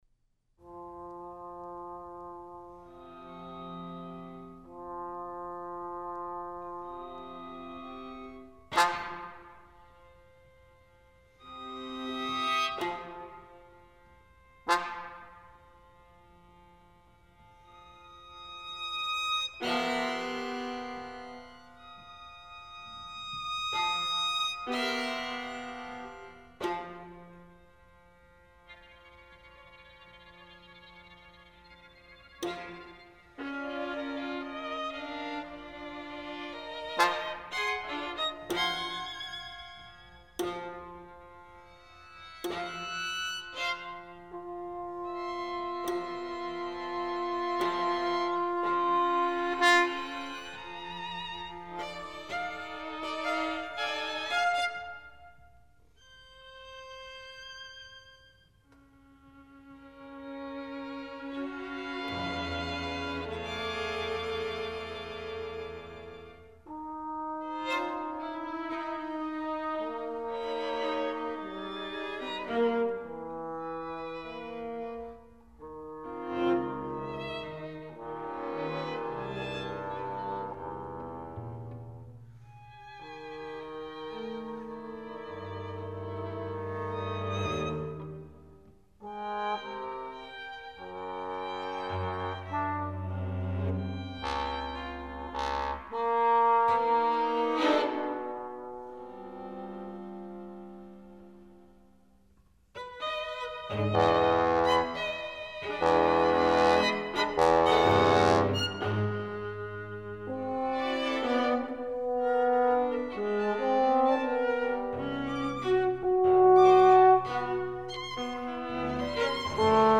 chamber composition
for bass trombone and string quartet